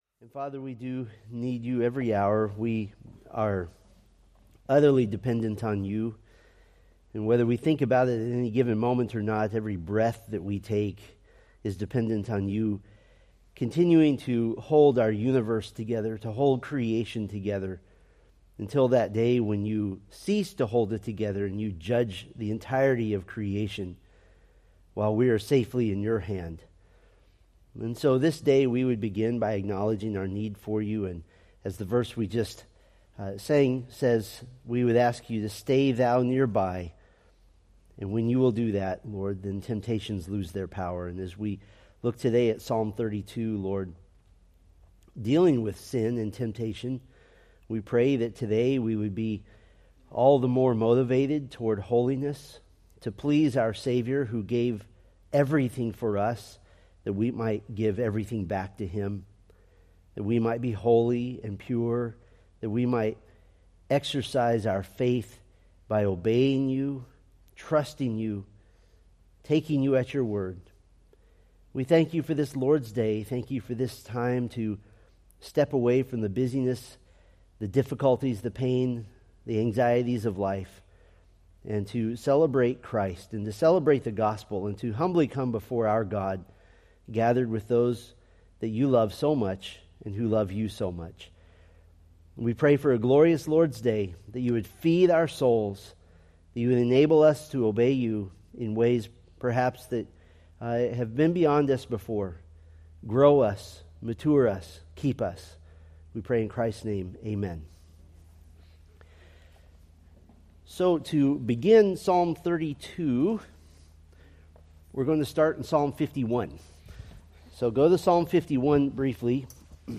Date: Nov 17, 2024 Series: Psalms Grouping: Sunday School (Adult) More: Download MP3